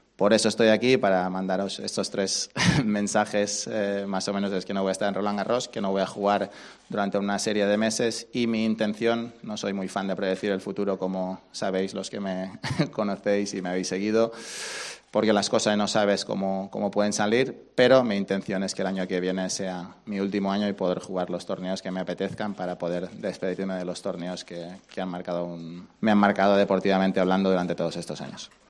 Rafa Nadal ha desvelado este jueves en su academia su plan para los próximos meses.